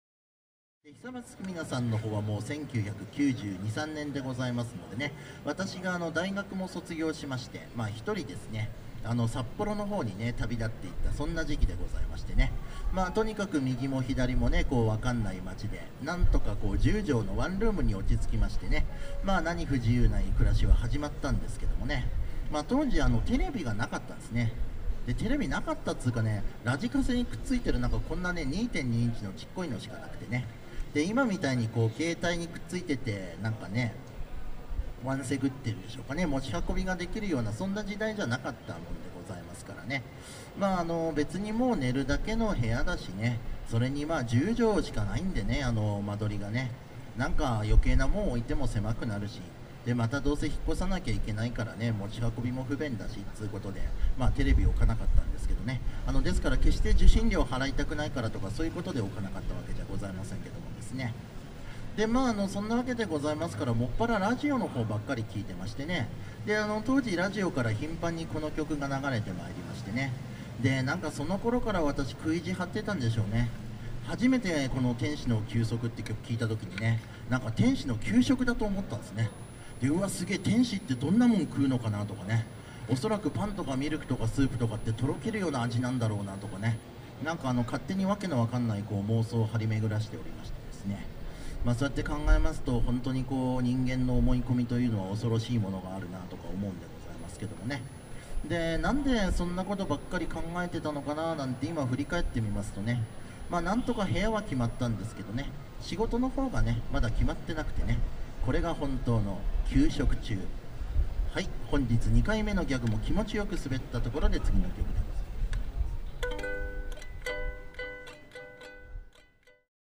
東京ストリートコーナーズ“ロジヨコライブ”レポート
→トークデータはこちら（ウインドウズメディア形式）